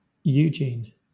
Ääntäminen
US : IPA : /juˈd͡ʒin/